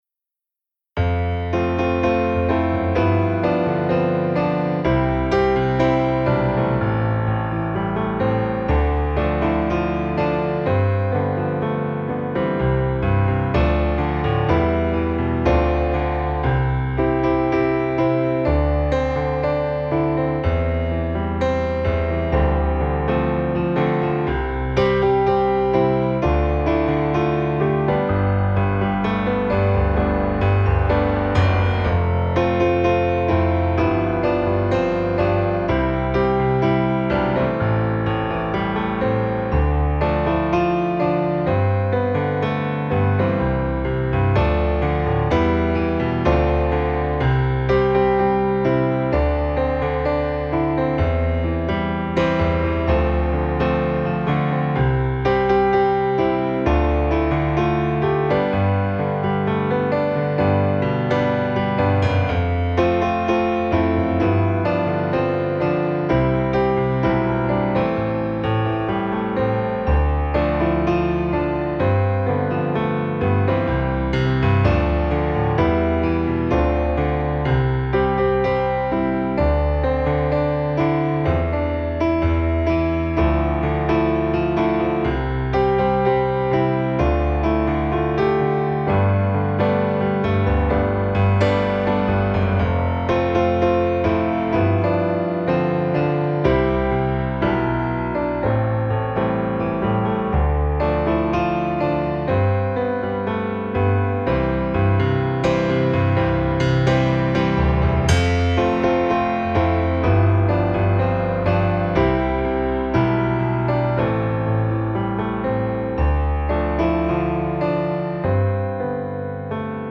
Zima (s playbackom)
pieseň zo seminára (2018 Banská Bystrica) – noty s akordami, prezentácia a playback